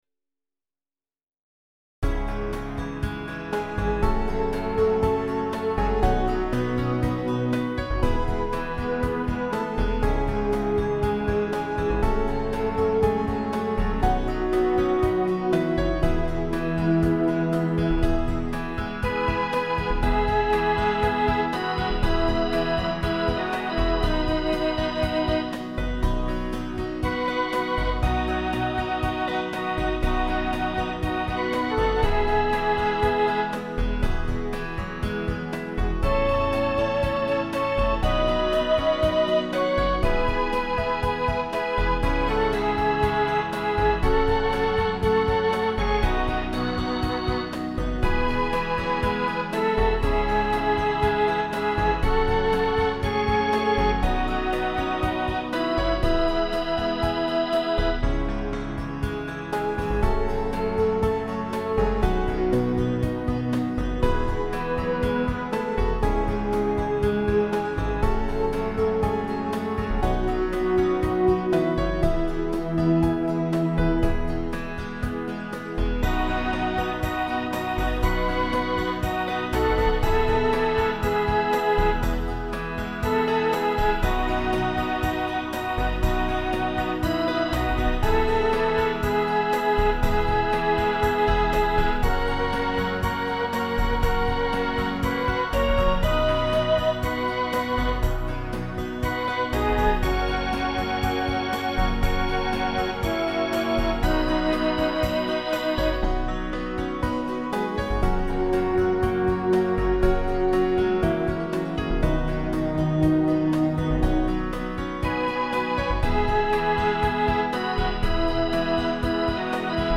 MP3 (instrumental)